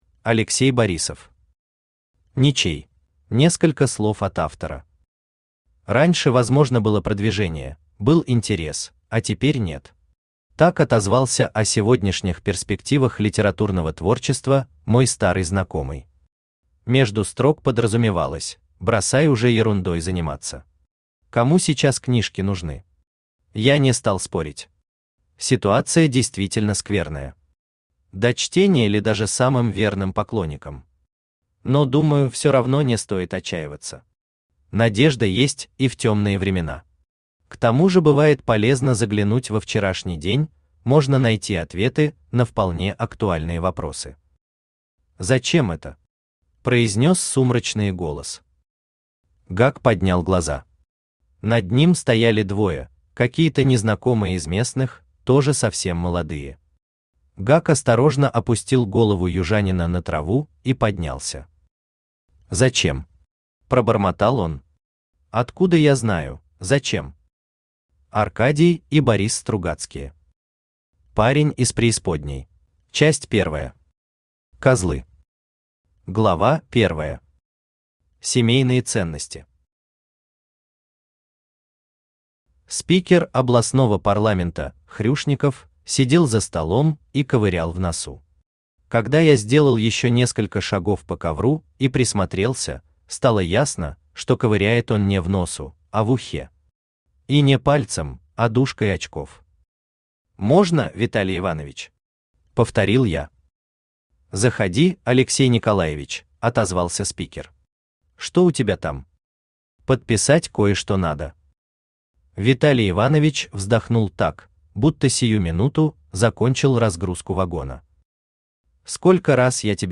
Аудиокнига Ничей | Библиотека аудиокниг
Aудиокнига Ничей Автор Алексей Борисов Читает аудиокнигу Авточтец ЛитРес.